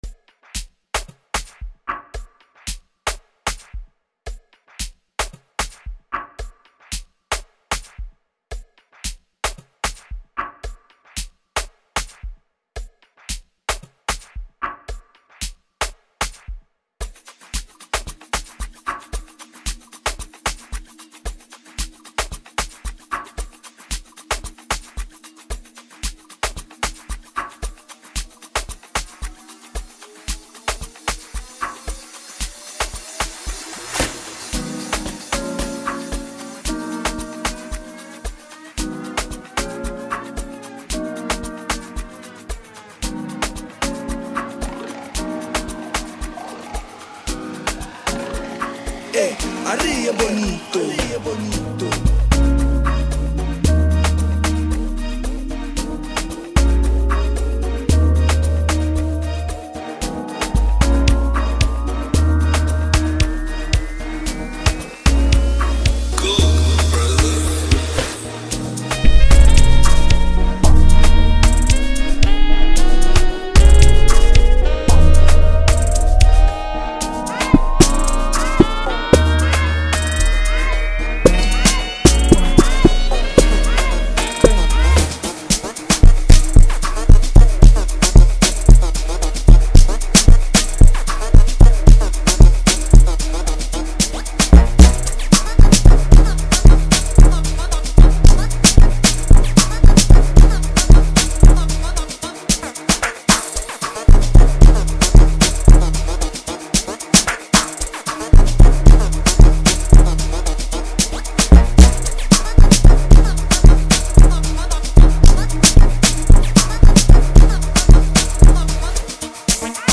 Home » Amapiano » Hip Hop